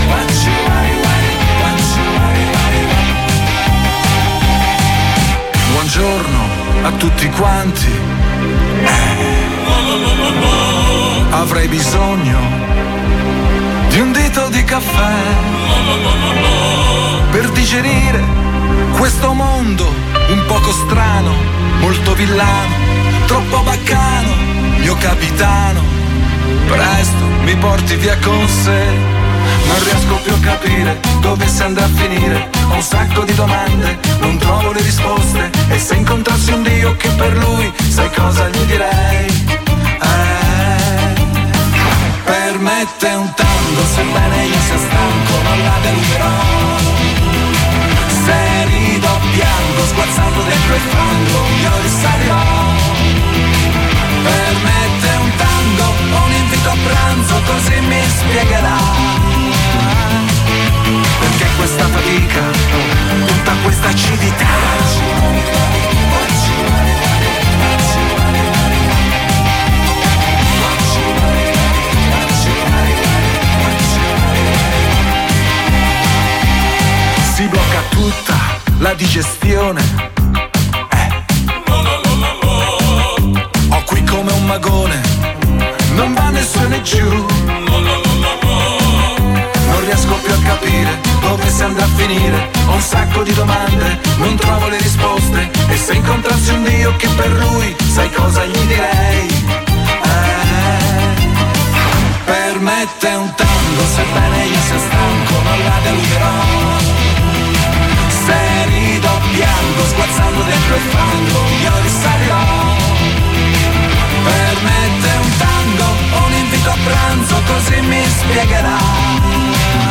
🎧 La Bandabardò torna con Fandango, il nuovo album. L'intervista